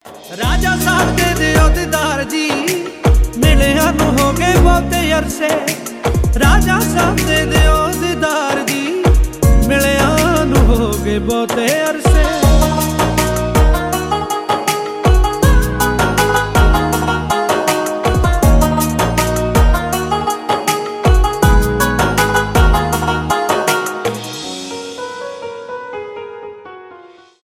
религиозные , зарубежные , поп